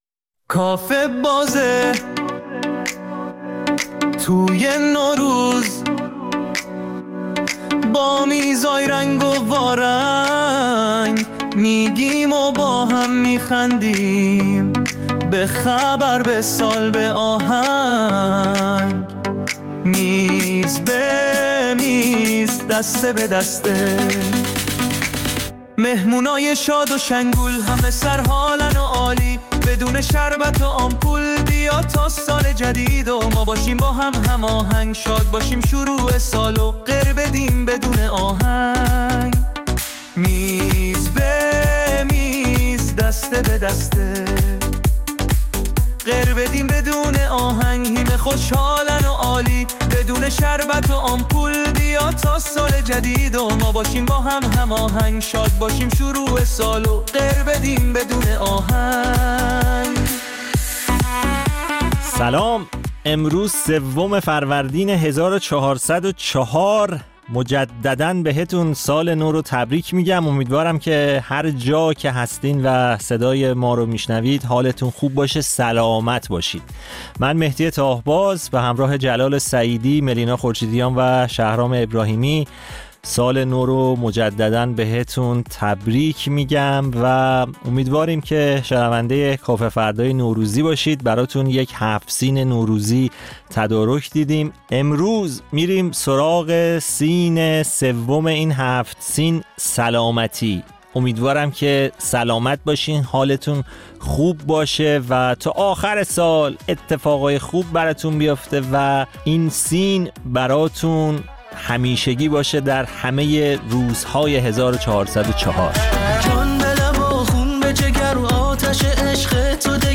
در کافه فردای نوروز با سفره هفت‌سین خاص کافه‌فردایی‌ها شوخی می‌کنیم و در میز‌های مختلف و بخش‌های متفاوت نمایشی وجوه متفاوت نوروز و تعطیلات نوروزی را در کنار هم به مرور می‌نشینیم.